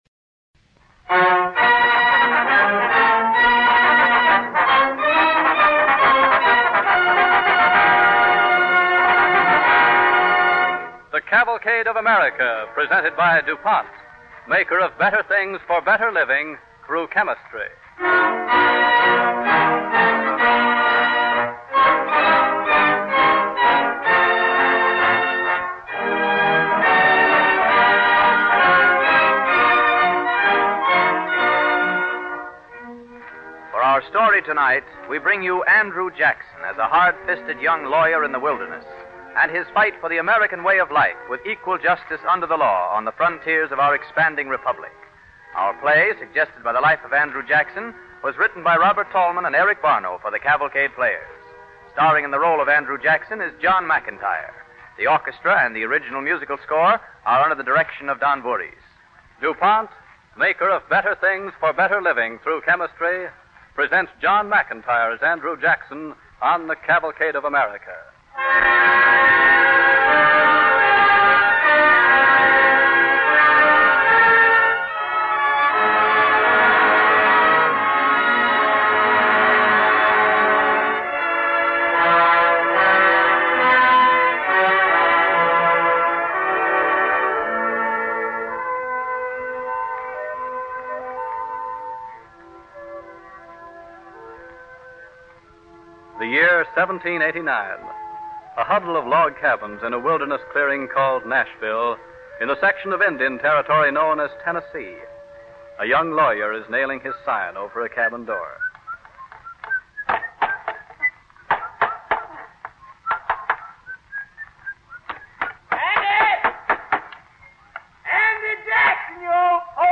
Listen to and download the Cavalcade of America Radio Program